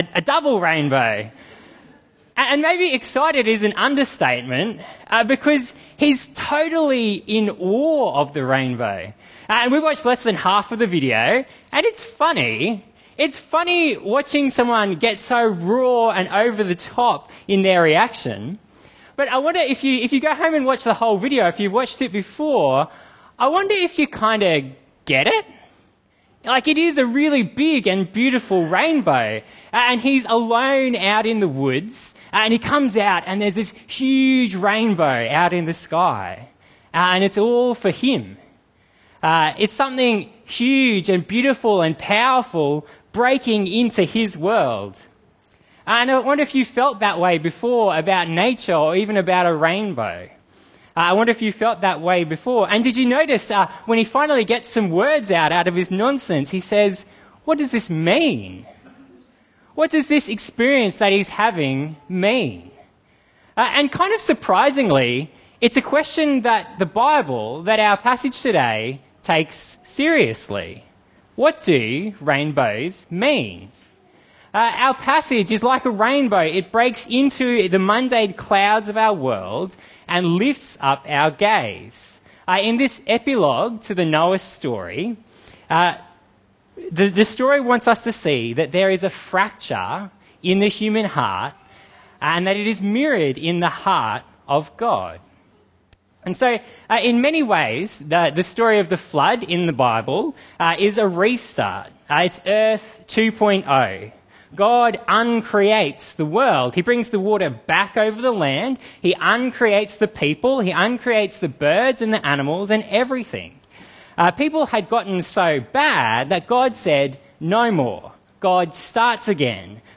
Bible Talks